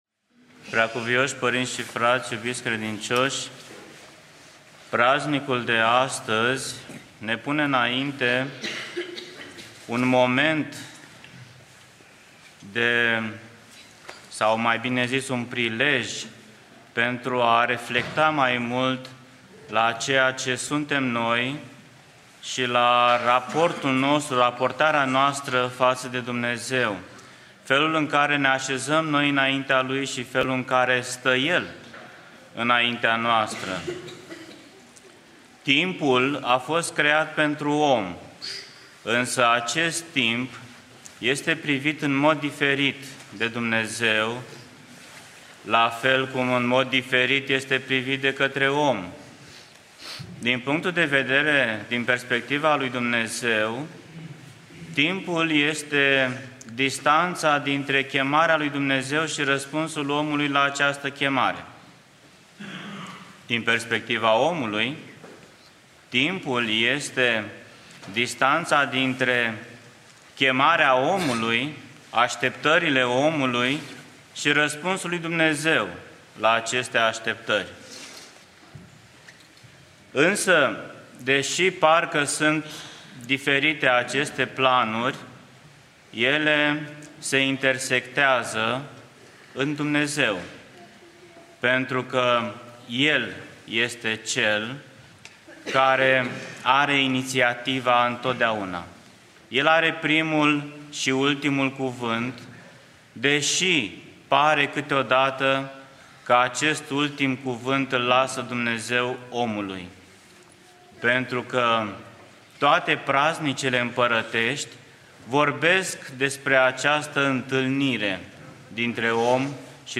Predică PF Daniel
Cuvânt după Sfânta Liturghie